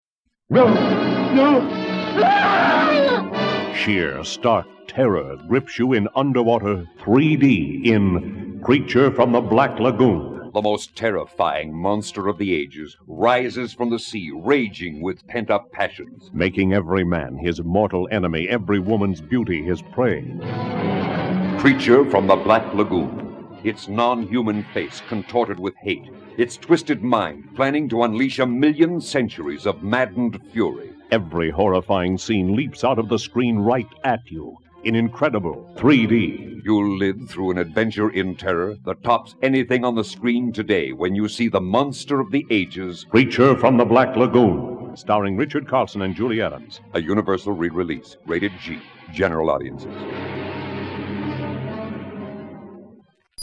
1972 3D Radio Spots